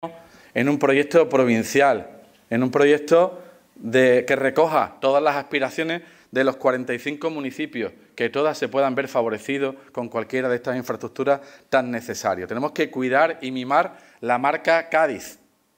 Ésa es la hoja de ruta que ha propuesto Juan Carlos Ruiz Boix, en el encuentro informativo organizado Europa Press en la sede de Cajasol de Jerez para el futuro de la provincia.